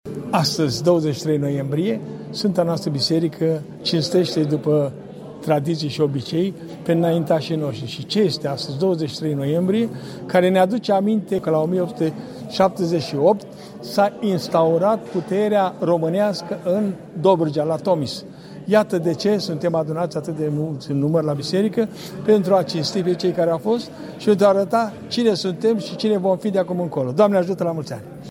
la Biserica „Metamorphosis”, cel mai vechi lăcaș ortodox din Constanța
a fost săvârșită tradiționala slujbă de mulțumire